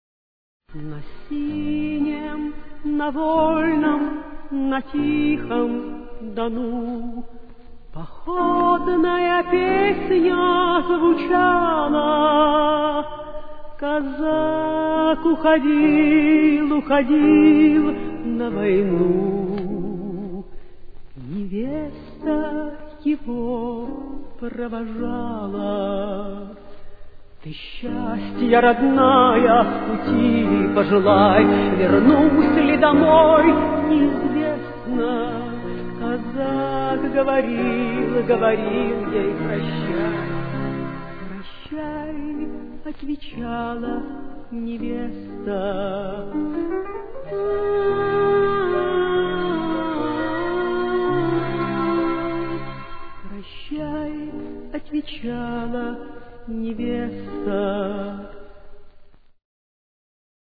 Соль минор.